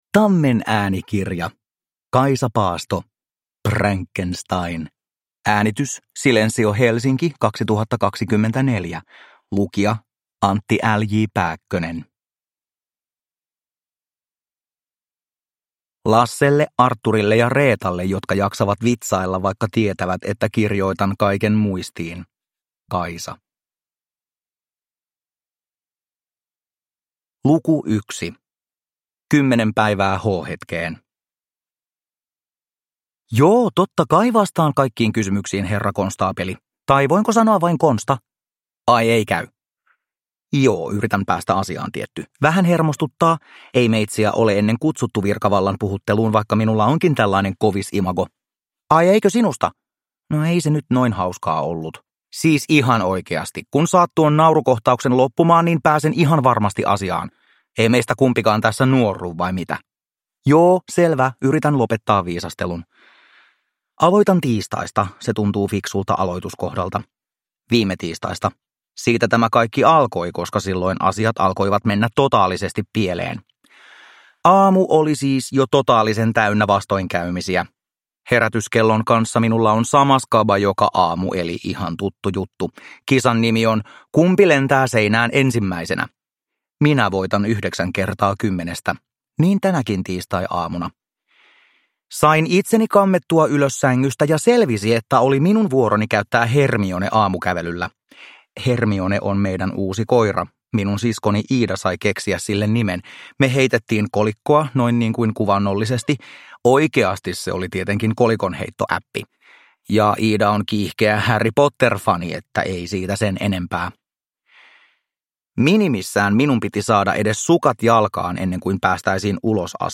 Prankenstein – Ljudbok